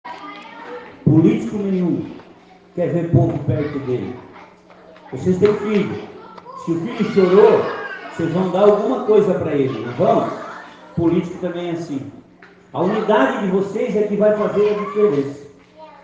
O vereador Salésio Lima (PSD), esteve na reunião realizada na terça-feira (19), discutindo com os pais e lideranças a situação da estrutura da Escola Amaro João Batista, no Bairro Nova Esperança e uma frase chamou a atenção de uma mãe que gravou o discurso do parlamentar.